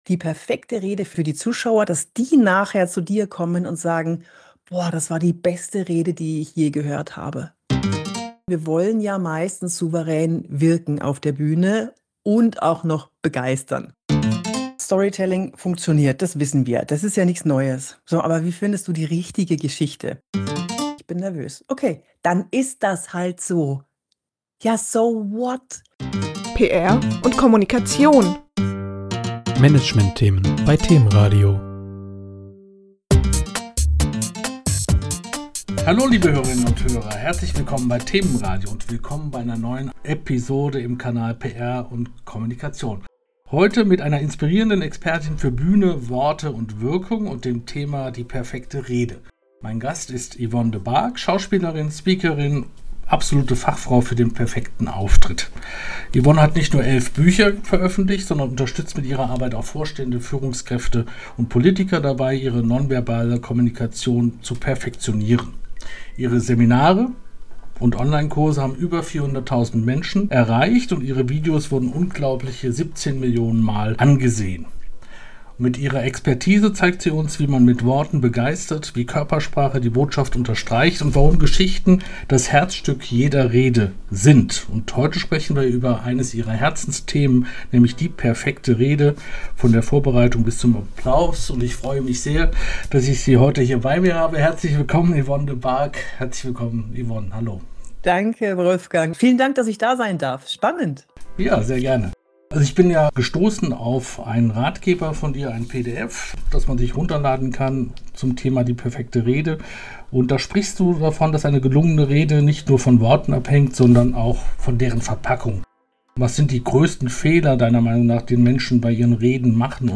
Mit ihrer Expertise zeigt sie uns, wie man mit Worten begeistert, wie Körpersprache die Botschaft unterstreicht und warum Geschichten das Herzstück jeder Rede sind. Heute sprechen wir mit ihr über ihr Herzensthema: Die perfekte Rede – von der Vorbereitung bis zum Applaus.